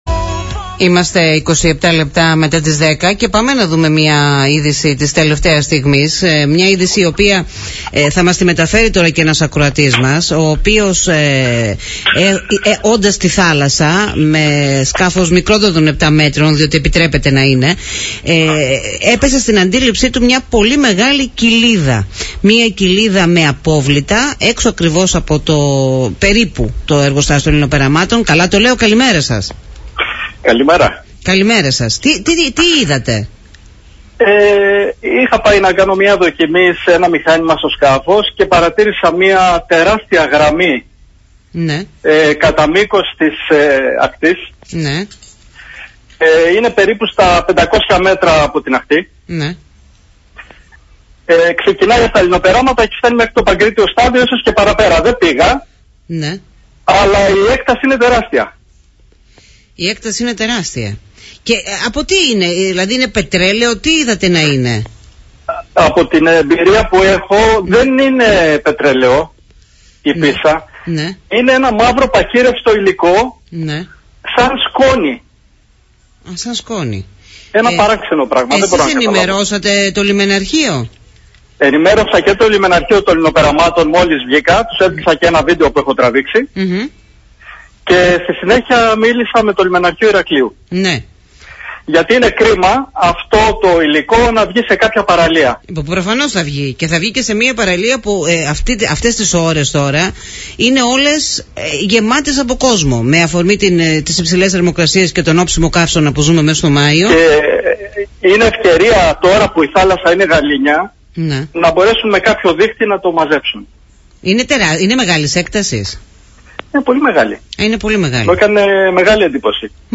Aκούστε εδώ τα όσα κατήγγειλε στον Politica 89.8 ακροατής για το παραπάνω περιστατικό: